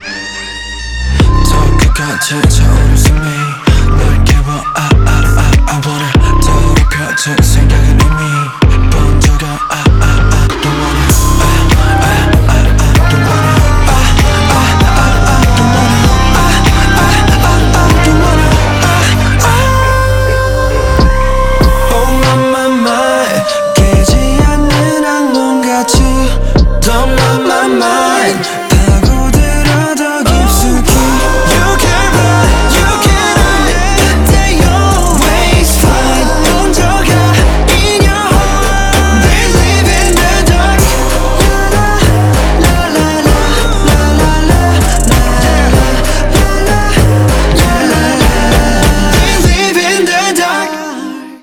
• Качество: 320, Stereo
свист
Trap
качающие
K-Pop